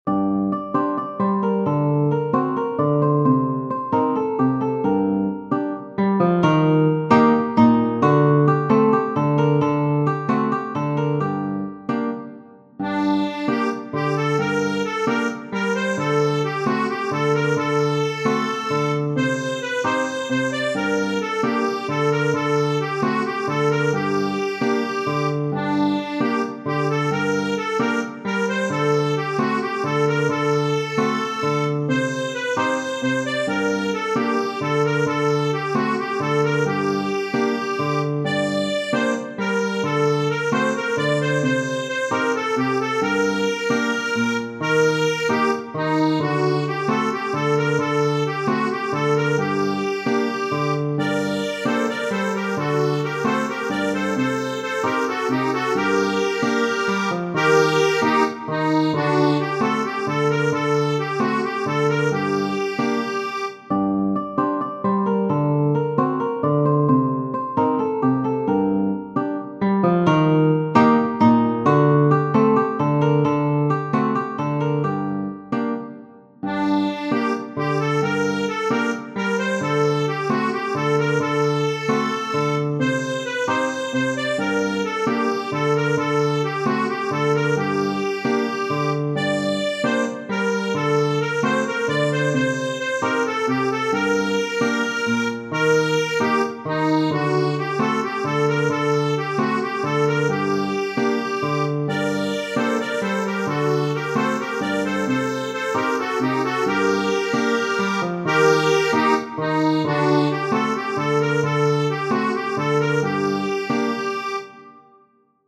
Tradizionale Genere: Folk Testo di anonimo Qysh n’vogli ne u deshtëm, Njeni tjetrin përqafu Zemrat tona jur pa jan’nda Përher’njena tjetrën dashunu.